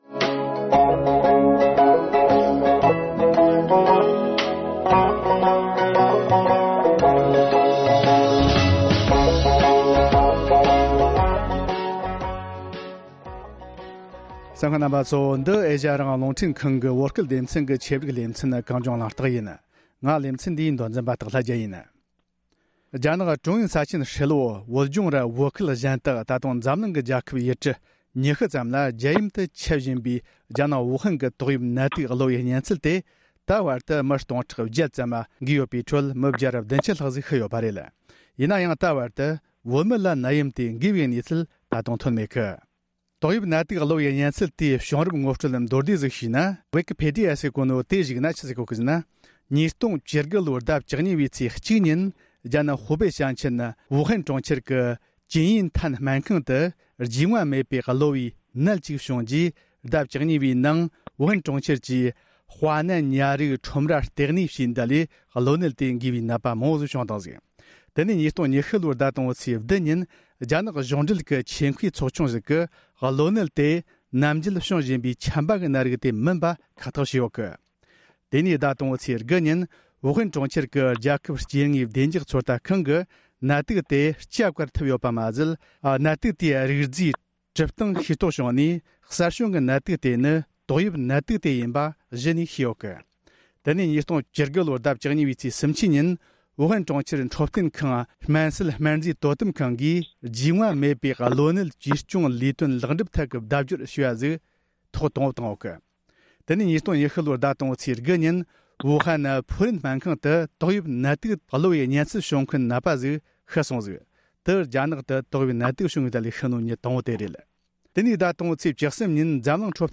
གལ་ཏེ་ནད་ཡམས་དེ་འགོས་པའི་དོགས་པ་བྱུང་ཚེ་ཅི་ཞིག་བྱེད་དགོས་པ་སོགས་ཀྱི་སྐོར་འབྲེལ་ཡོད་ཆེད་མཁས་སྨན་པ་གཉིས་དང་མཉམ་དུ་བགྲོ་གླེང་བྱེད་རྒྱུ་ཡིན་ལ།